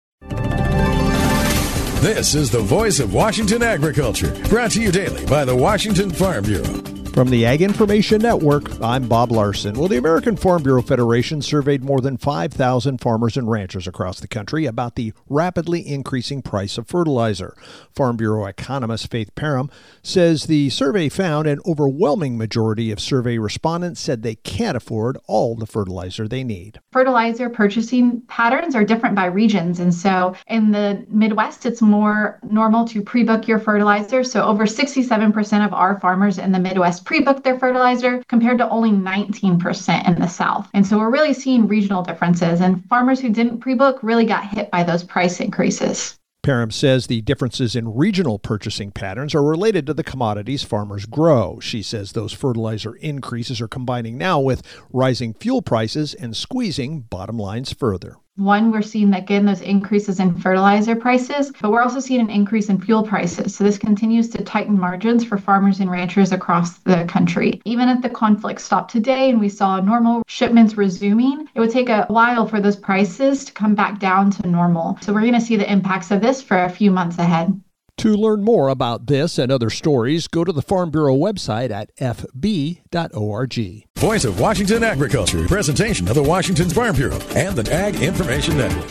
Monday Apr 20th, 2026 55 Views Washington State Farm Bureau Report